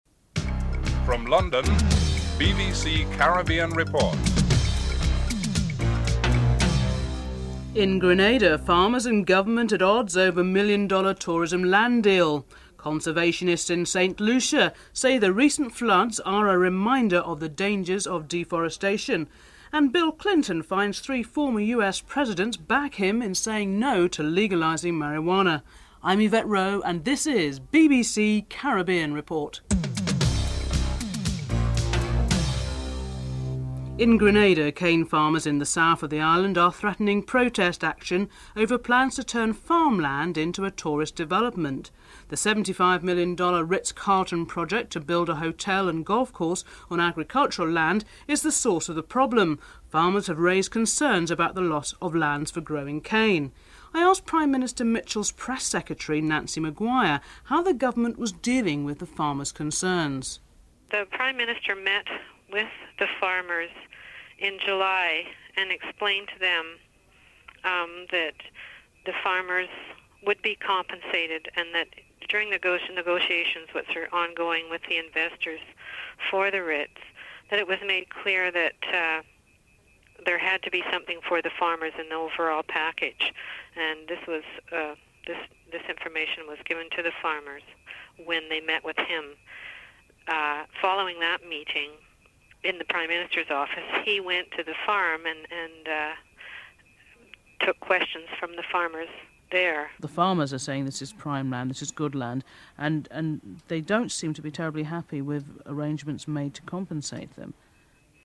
1. Headlines (00:00-00:32)